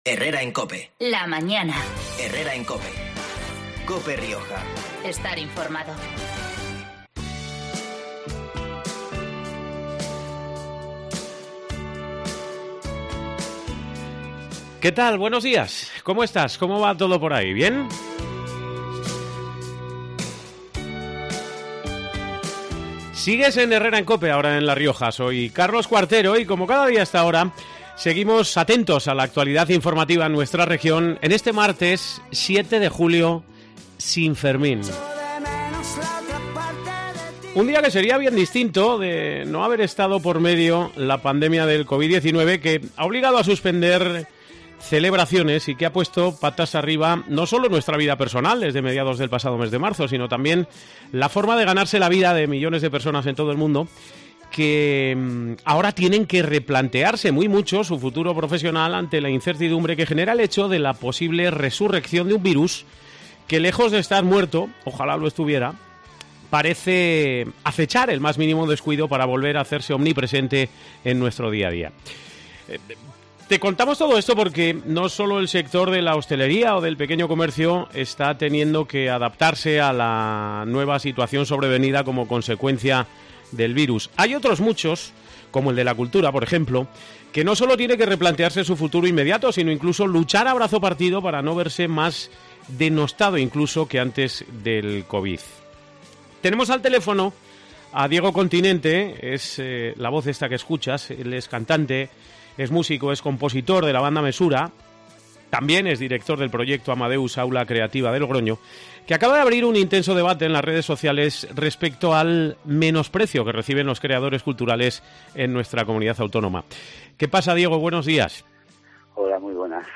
Este mediodía ha concedido una entrevista a COPE Rioja en la que hemos abordado estos asuntos.